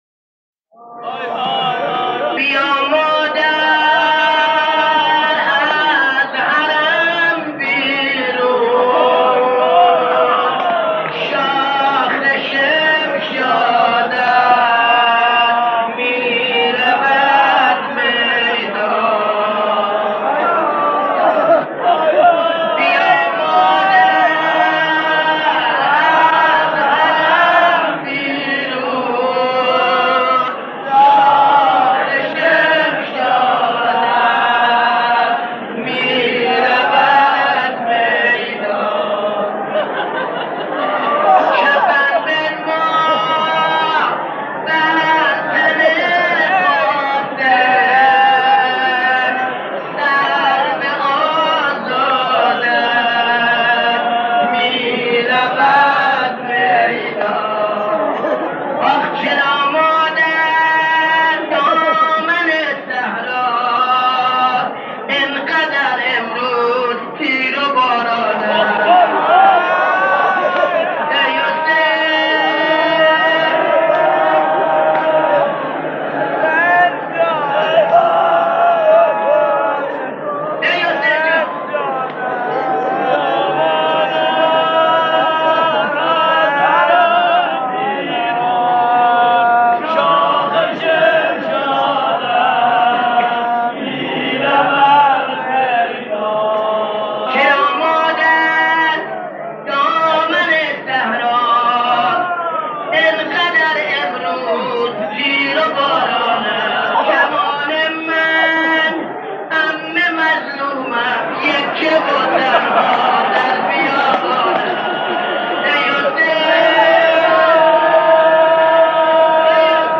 در پرده عشاق، صدای مداحان و مرثیه‌خوانان گذشته تهران قدیم را خواهید شنید که صدا و نفسشان شایسته ارتباط دادن مُحب و مَحبوب بوده است.
مصیبت‌خوانی درباره شهادت حضرت قاسم (ع) فرزند نوجوان امام حسن (ع)